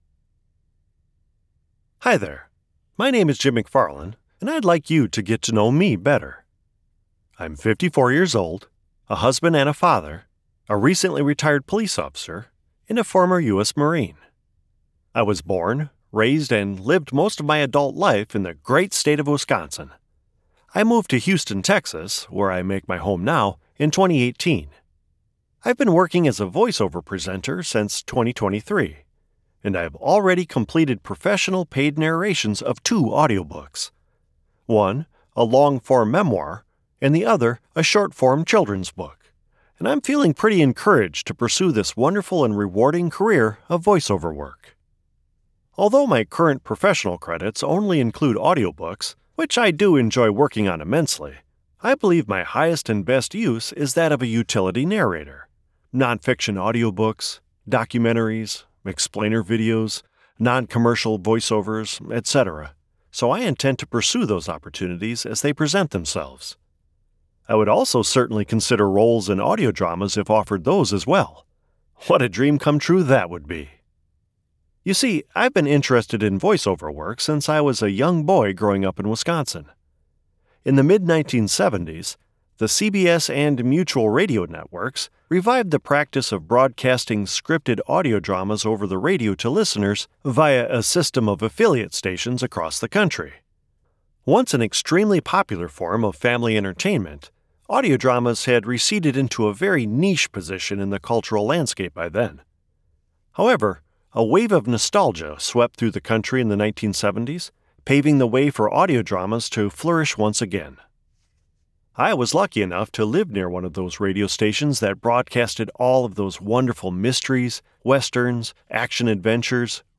North American (General); North American (Midwest)
Middle Aged